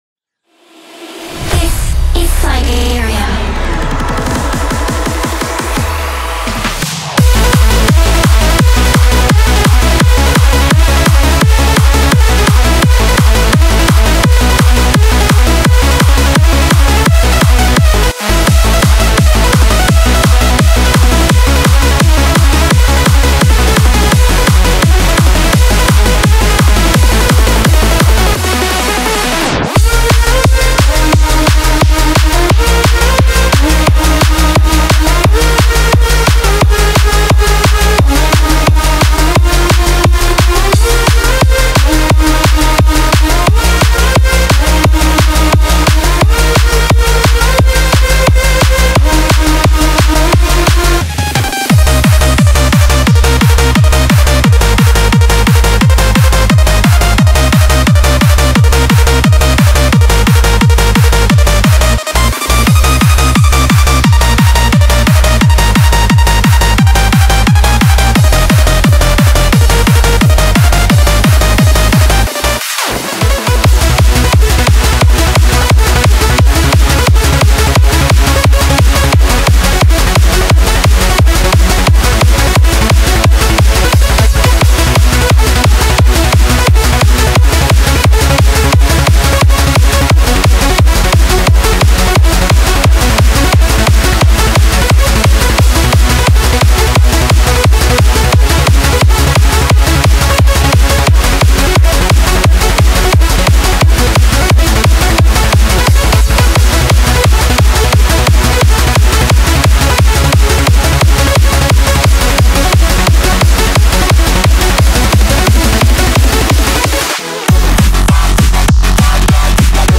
UK/Happy Hardcore
J-Core, Techcore, Future Core
Freeform, Hypertrance, Hardtek
BPM Ranqe 140-235 (Focus 160-175)